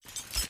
zipline_in.wav